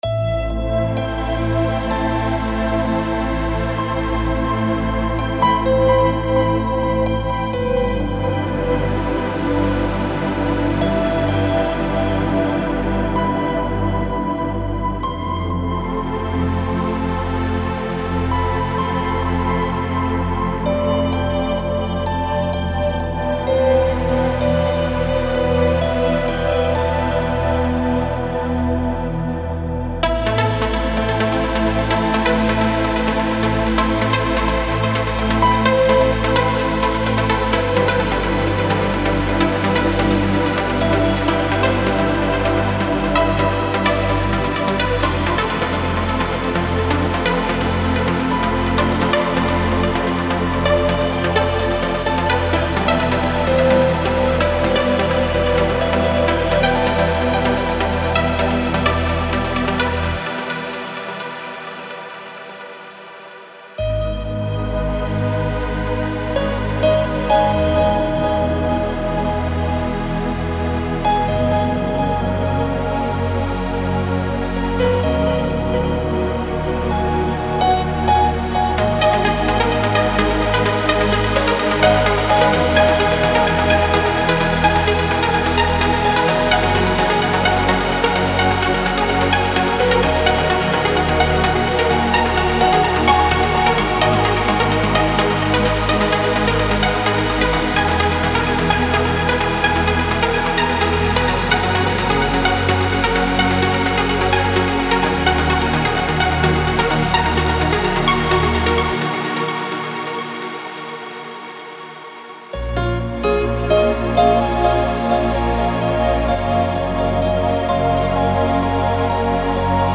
Piano, Lead, Pad for any Kit ( MIDI )